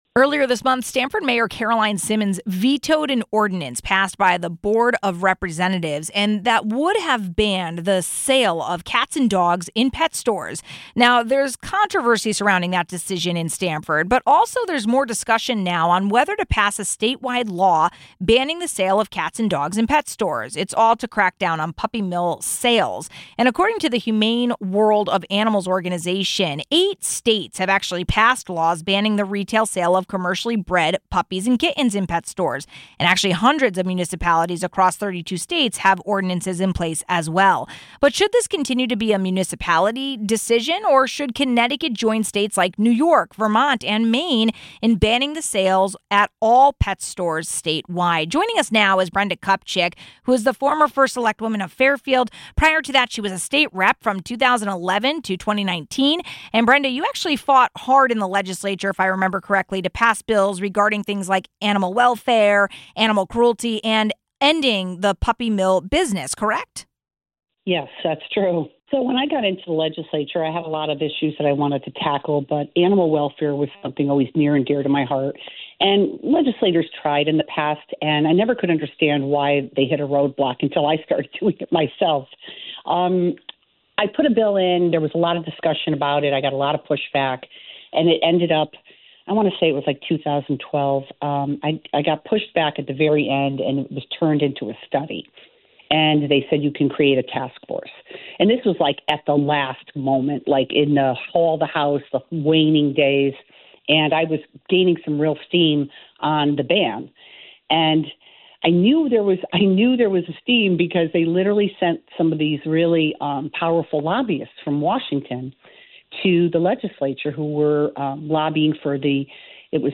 Should this continue to be a municipality decision or should Connecticut join states like New York, Vermont, and Maine banning the sales at all pet stores. We got perspective from former State Representative Brenda Kupchick.